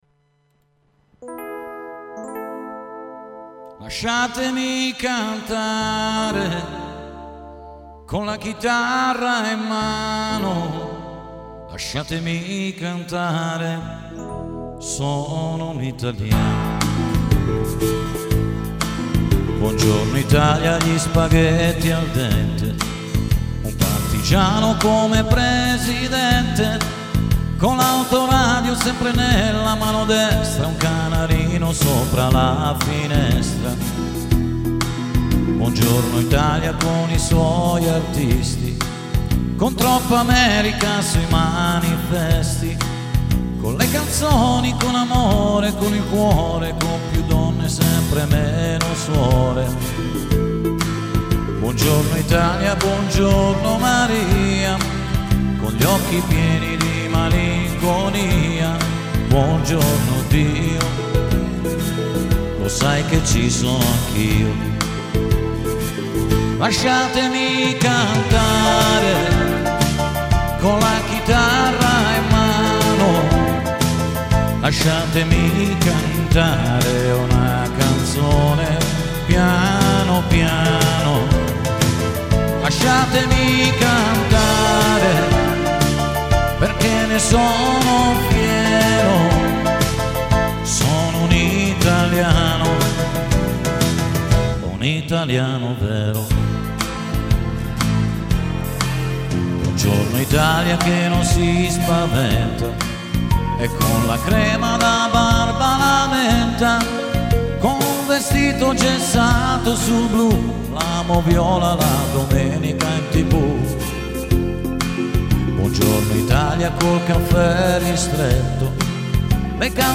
Sänger One Man Band - International Musik
• One-Man-Bands